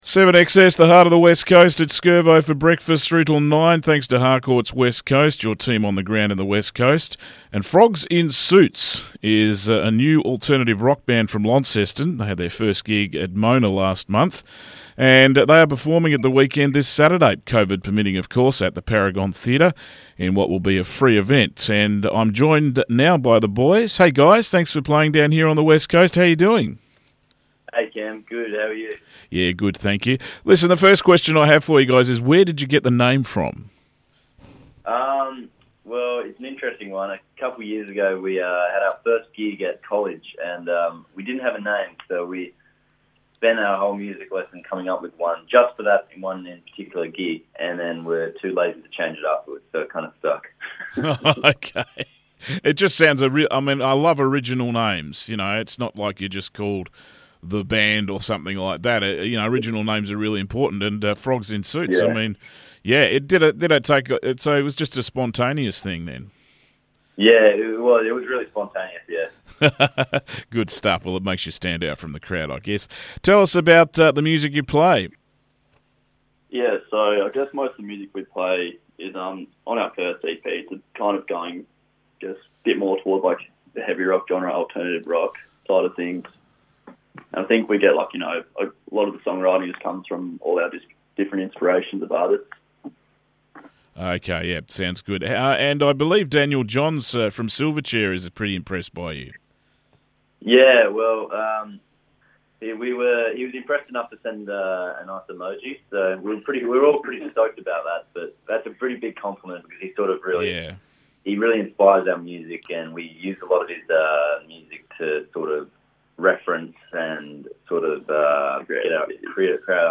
Frogs in Suits Interview